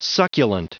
Prononciation du mot succulent en anglais (fichier audio)
Prononciation du mot : succulent